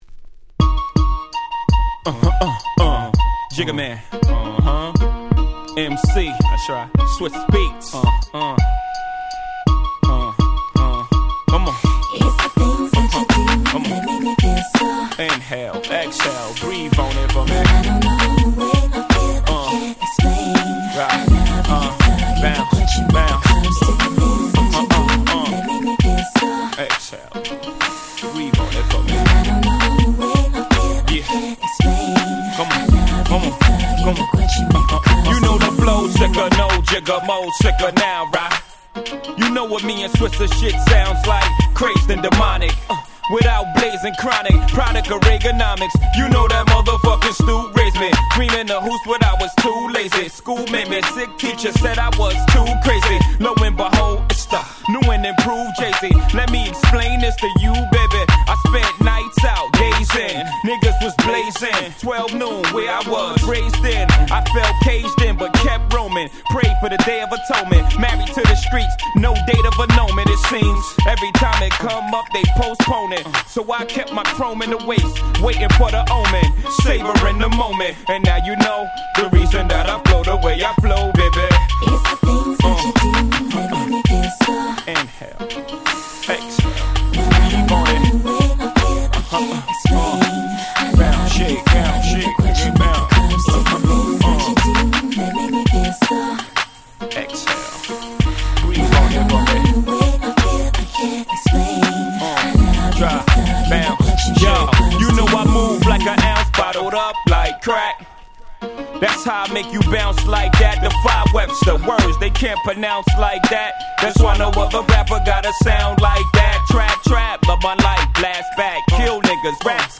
99' Smash Hit Hip Hop !!